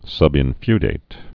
(sŭbĭn-fydāt) also sub·in·feud (-fyd)